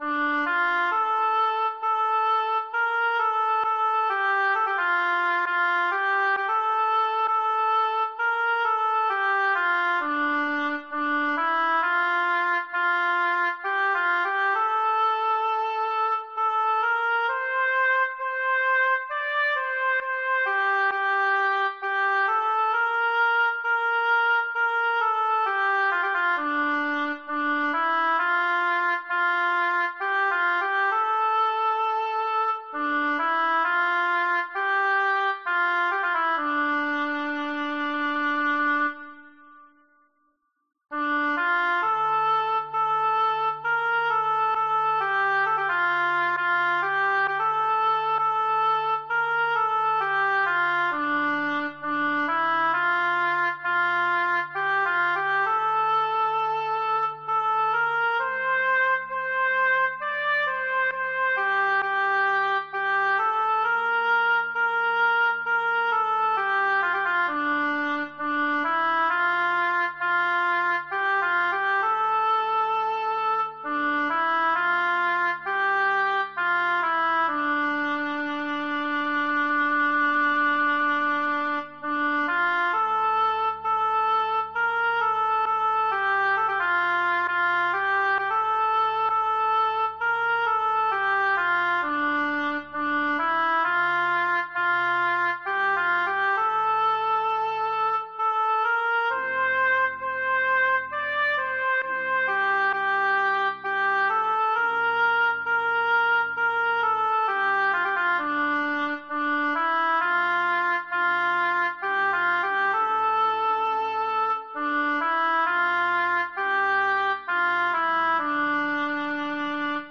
- Hymne du soir en norvégien